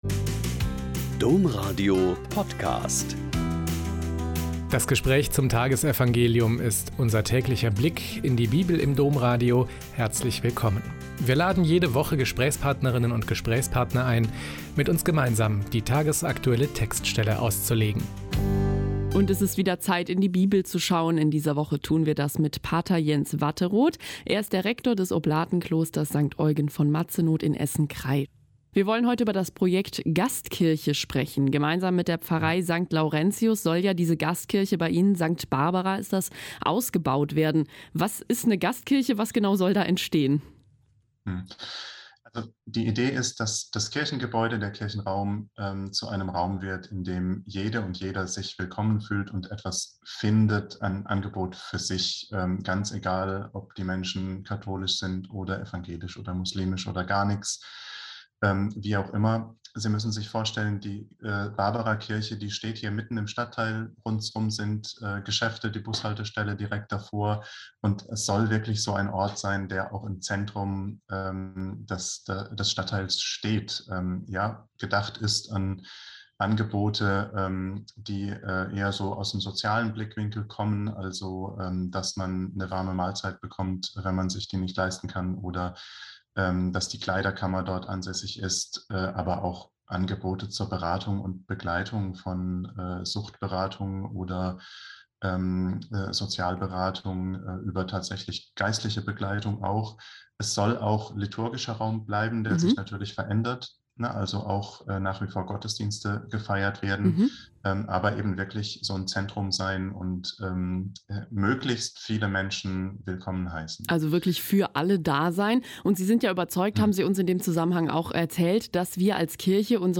Mt 20,17-28- Gespräch